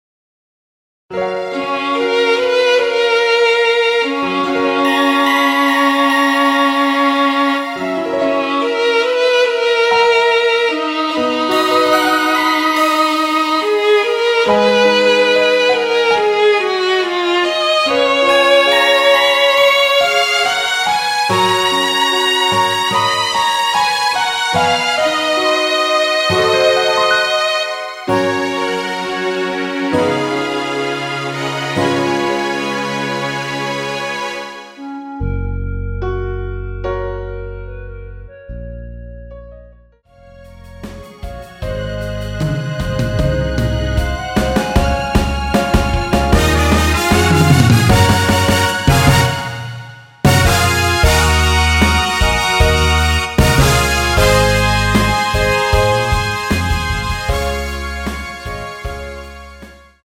원키에서(+5)올린 멜로디 포함된 MR입니다.
F#
앞부분30초, 뒷부분30초씩 편집해서 올려 드리고 있습니다.
중간에 음이 끈어지고 다시 나오는 이유는